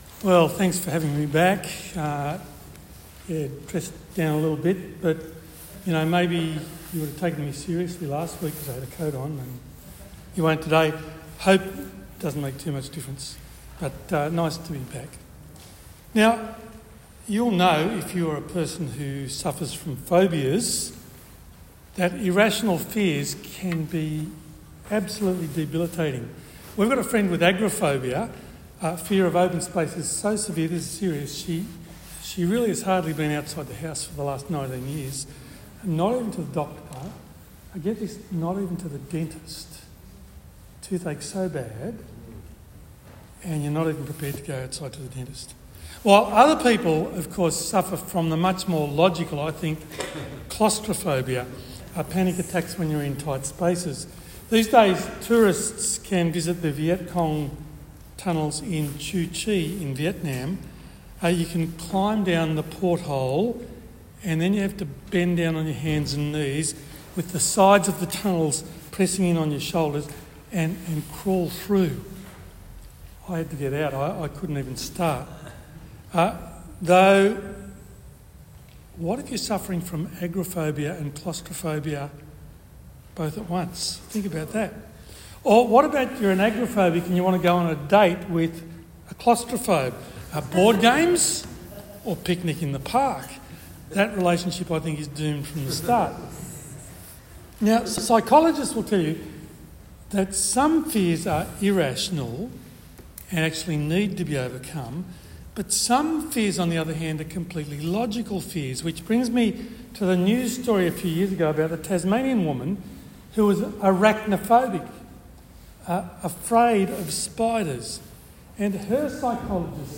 Proverbs 21:1-19 Service Type: Tuesday Bible Talk